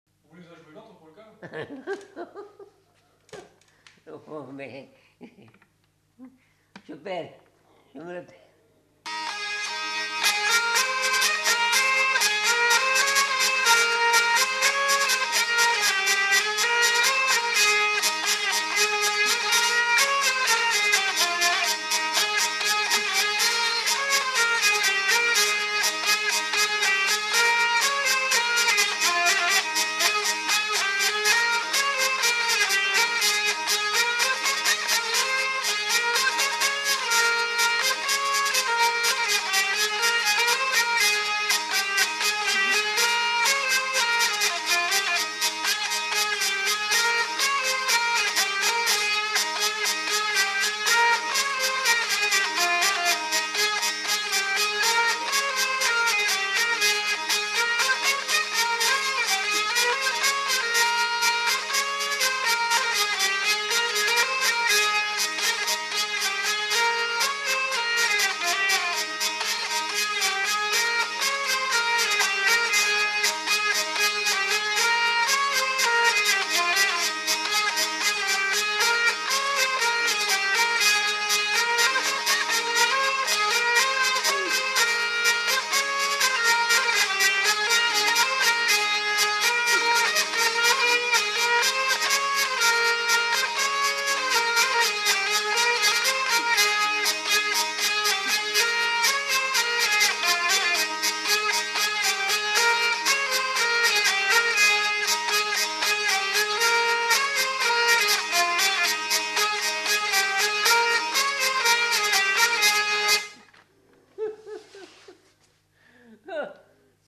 Huit airs à danser interprétés à la vielle à roue
enquêtes sonores